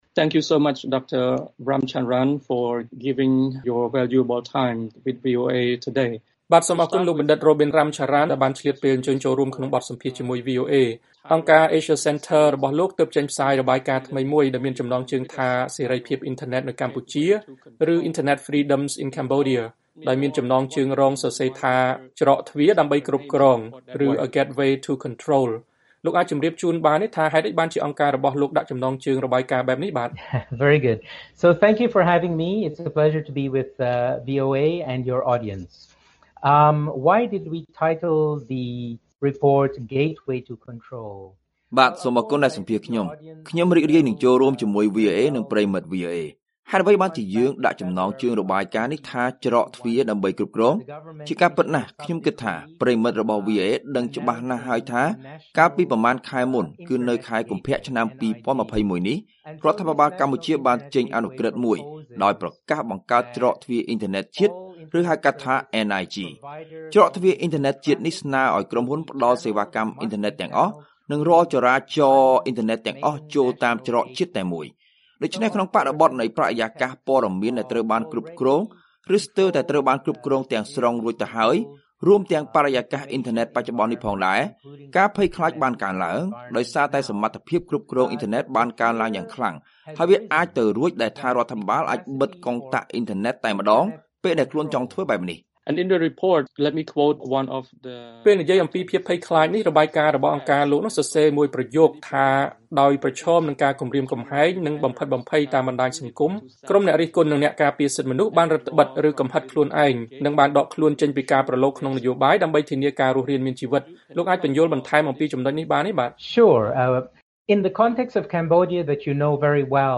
បទសម្ភាសន៍ VOA៖ អ្នកជំនាញថាច្រកទ្វារអ៊ីនធឺណិតកម្ពុជា នាំមកនូវការភ័យខ្លាចកាន់តែខ្លាំង